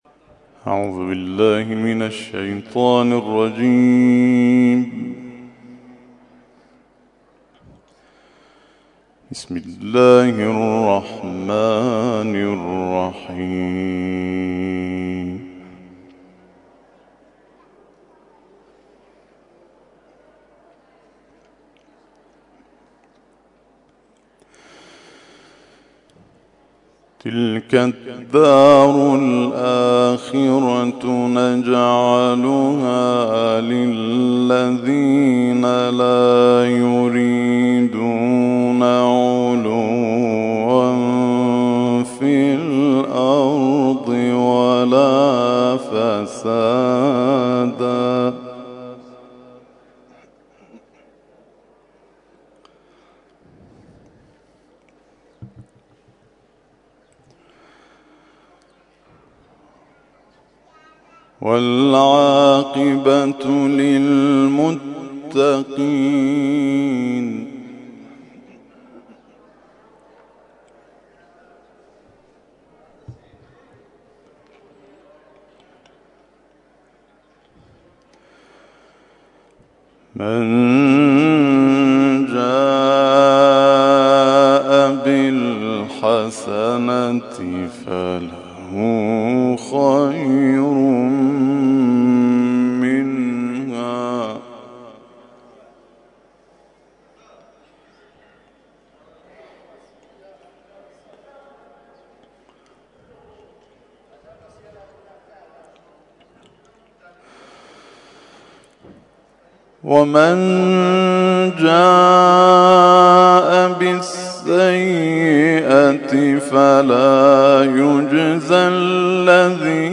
تلاوت ظهر
تلاوت مغرب